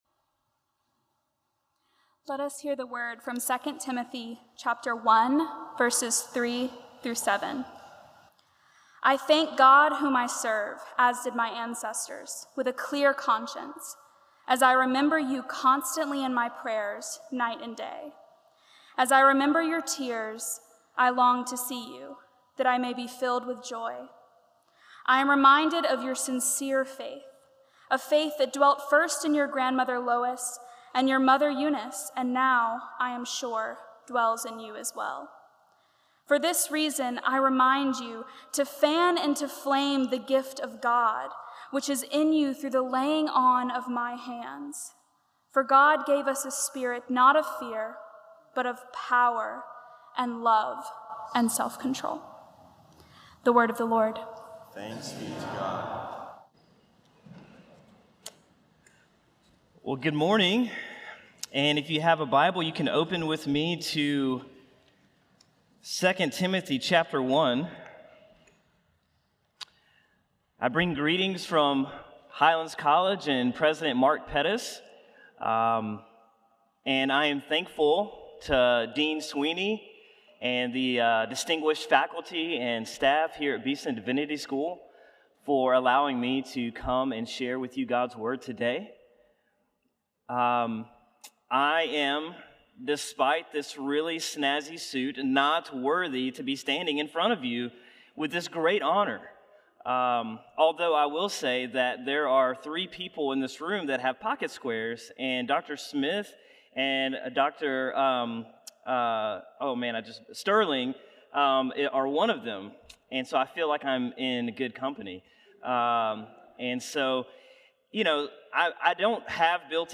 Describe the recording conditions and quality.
Beeson Divinity School Chapel Services